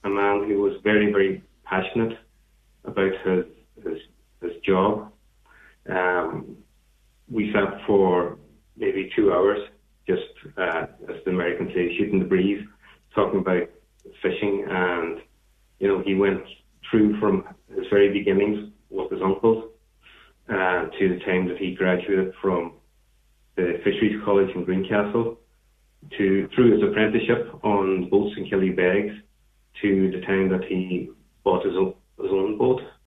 on this morning’s Nine til Noon show.